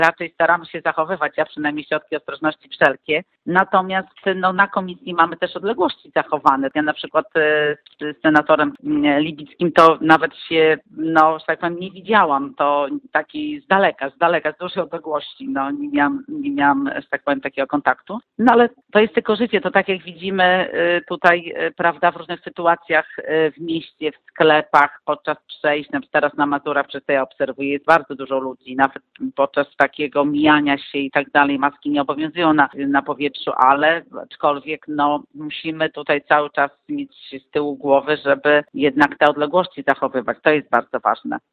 Ważne jest, abyśmy zachowywali zalecenia sanitarne – dodaje senator Kopiczko.